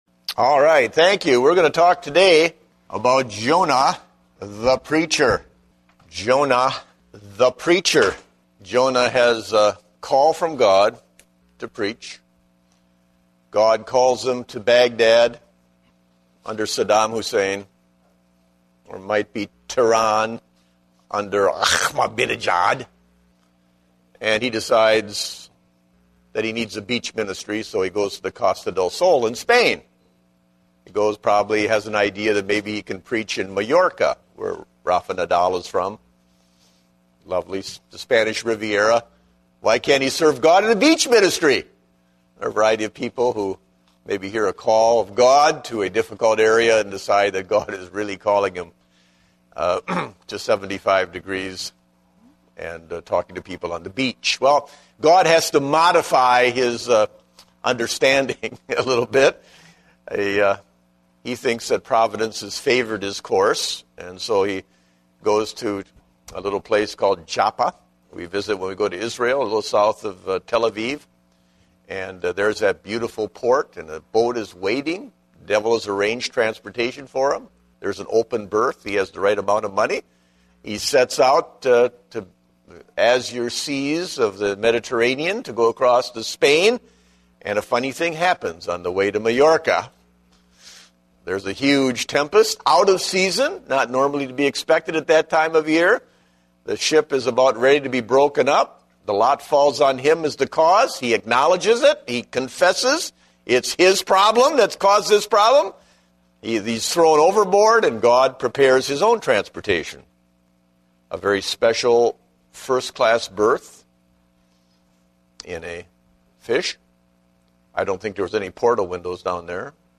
Date: October 17, 2010 (Adult Sunday School)